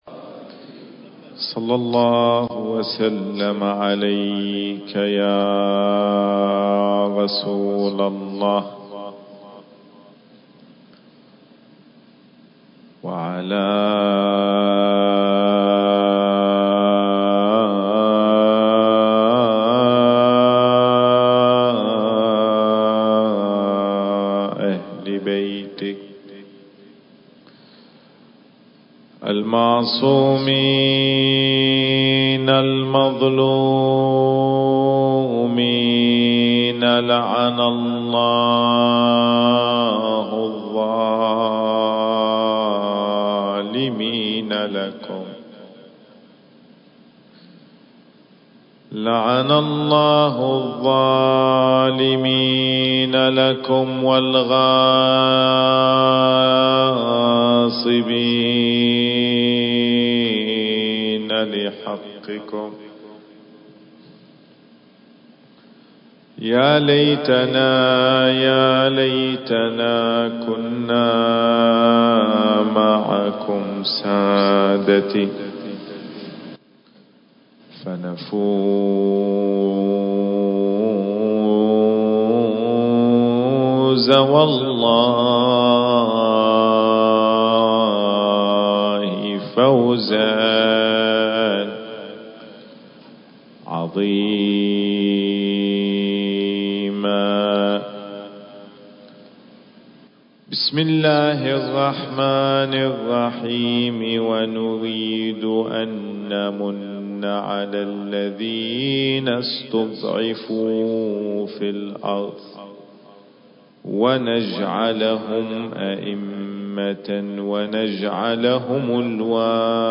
المكان: مأتم الزهراء (عليها السلام) / صفوى التاريخ: 10 محرم الحرام/ 1442 للهجرة المحاور: - ما هي علامات الظهور؟ - محاذير في التعامل مع علامات الظهور.